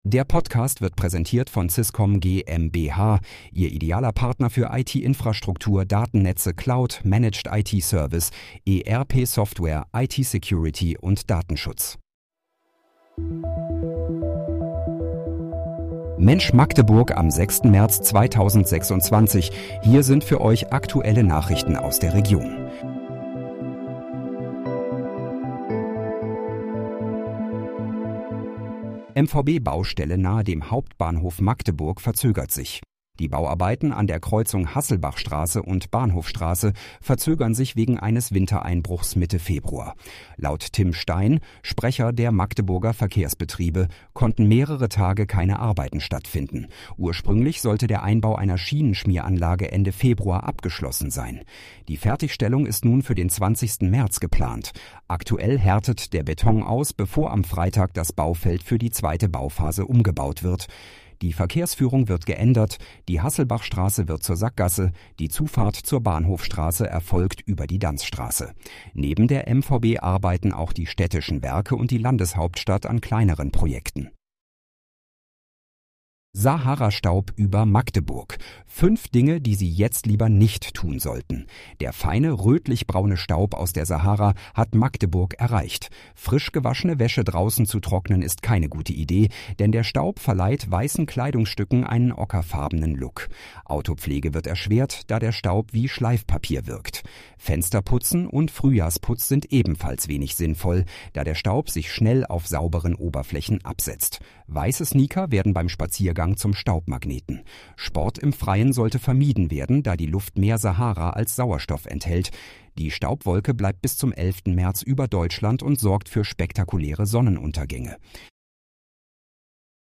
Mensch, Magdeburg: Aktuelle Nachrichten vom 06.03.2026, erstellt mit KI-Unterstützung